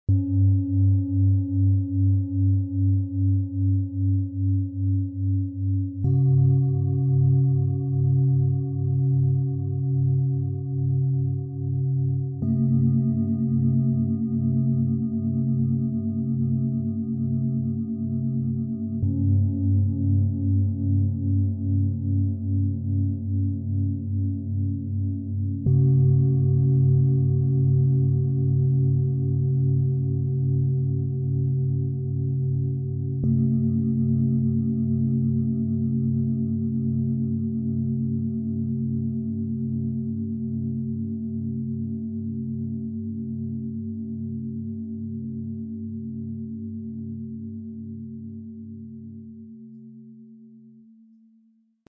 Körper stärken, Geist klären und die Seele berühren - Klangmassage Set aus 3 Biorhythmus - Klangschalen - harmonischer Sein, Ø 17,3 -23,1 cm, 2,89 kg
Die kleinste Schale schwingt hoch und fein.
Mit Sorgfalt und überliefertem Wissen geformt, entfalten sie eine kraftvolle und präzise Resonanz.
Der integrierte Sound-Player - Jetzt reinhören ermöglicht das Anhören des Original-Tons dieser einzigartigen Schalen im Set - echt und unverfälscht.
Ein passender Schlägel wird mitgeliefert und bringt das Set warm und stimmig zum Klingen.
Tiefster Ton: Biorhythmus Körper, Biorhythmus Geist, Sonne
Mittlerer Ton: Biorhythmus Geist
Höchster Ton: Biorhythmus Seele